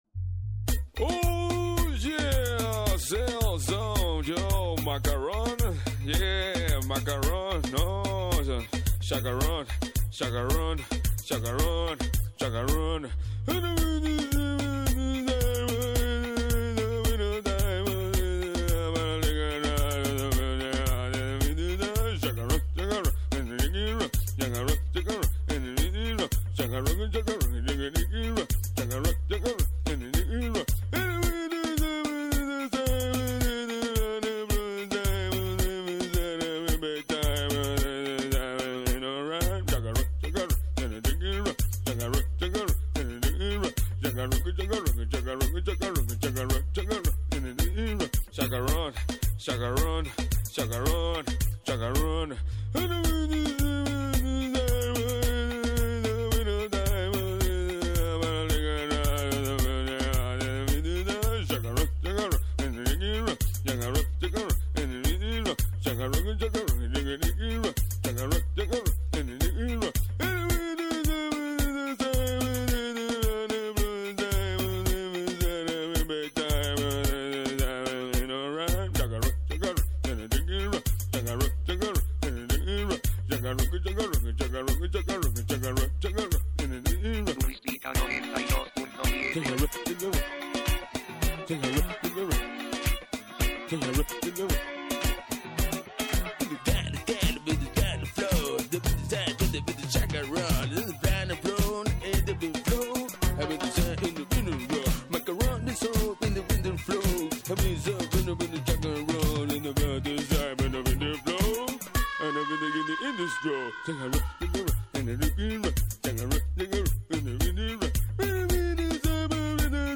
hip hop composition
main vocals